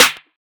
SNARE 16.wav